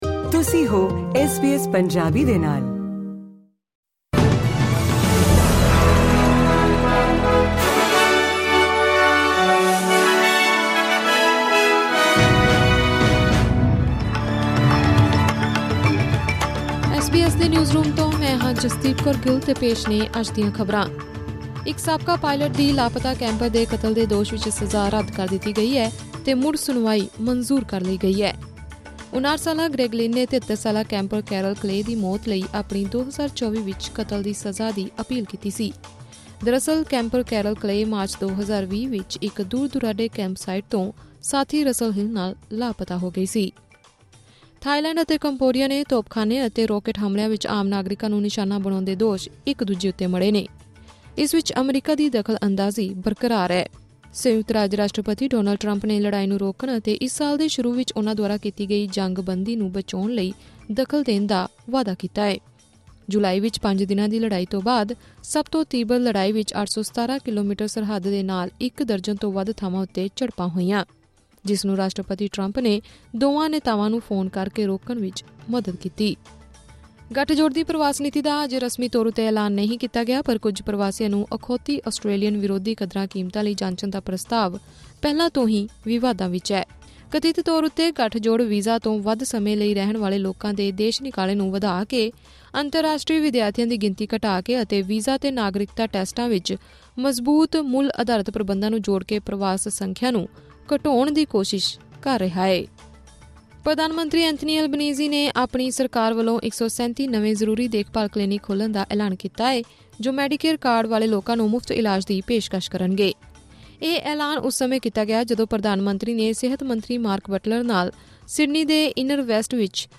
ਵੀਜ਼ਾ ਤੋਂ ਵੱਧ ਸਮੇਂ ਲਈ ਰਹਿਣ ਵਾਲੇ ਲੋਕਾਂ ਦੇ ਦੇਸ਼ ਨਿਕਾਲੇ ਨੂੰ ਵਧਾ ਕੇ, ਅੰਤਰਰਾਸ਼ਟਰੀ ਵਿਦਿਆਰਥੀਆਂ ਦੀ ਗਿਣਤੀ ਘਟਾ ਕੇ ਅਤੇ ਵੀਜ਼ਾ ਅਤੇ ਨਾਗਰਿਕਤਾ ਟੈਸਟਾਂ ਵਿੱਚ ਮਜ਼ਬੂਤ ਮੁੱਲ-ਅਧਾਰਤ ਪ੍ਰਬੰਧਾਂ ਨੂੰ ਜੋੜ ਕੇ ਪ੍ਰਵਾਸ ਸੰਖਿਆ ਨੂੰ ਘਟਾਉਣ ਦੀ ਕੋਸ਼ਿਸ਼ ਵਾਲੀ ਗੱਠਜੋੜ ਦੀ ਮਾਈਗ੍ਰੇਸ਼ਨ ਨੀਤੀ ਦਾ ਰਸਮੀ ਤੌਰ 'ਤੇ ਅਜੇ ਐਲਾਨ ਹੋਣਾ ਬਾਕੀ ਹੈ। ਇਸ ਬਾਰੇ ਨੈਸ਼ਨਲ ਸੈਨੇਟ ਲੀਡਰ ਬ੍ਰਿਜੇਟ ਮਕੈਂਜ਼ੀ ਨੇ ਮੀਡੀਆ ਨਾਲ ਗੱਲਬਾਤ ਕੀਤੀ।